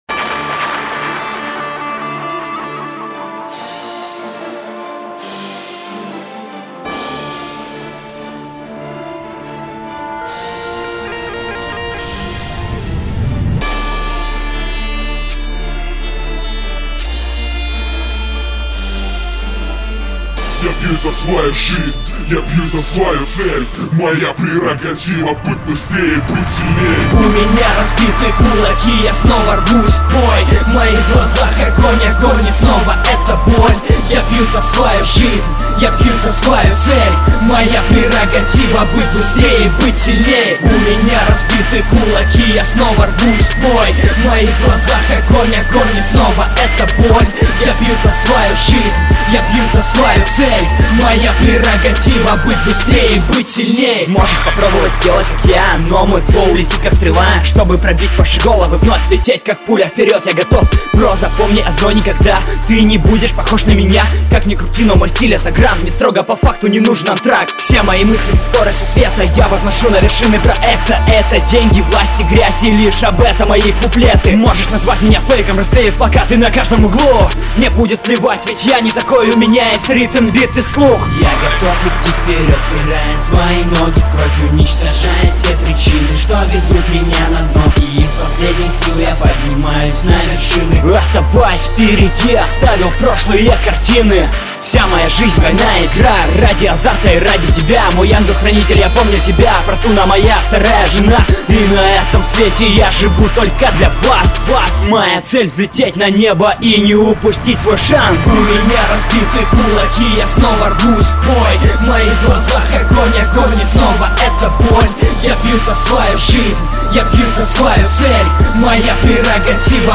rep_pro_sport_motivaciya_dlya_trenirovok.mp3